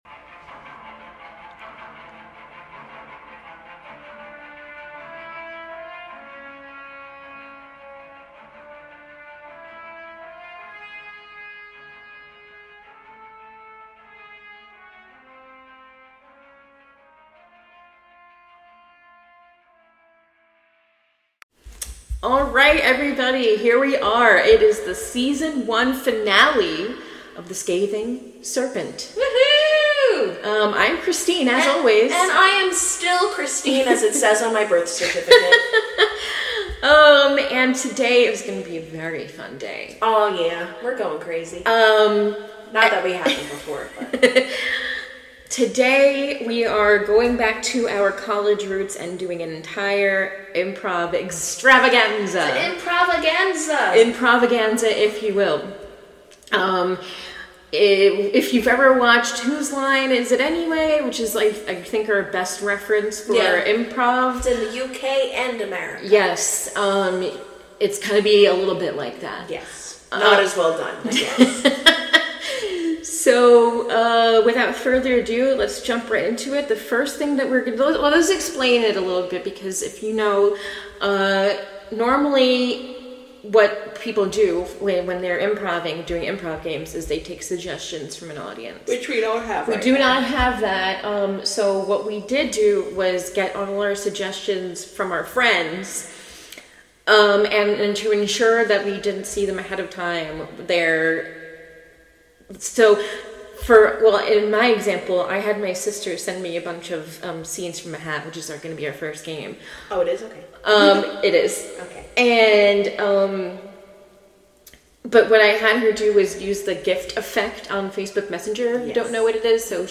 Headphone Warning: Loud outbursts randomly throughout episode.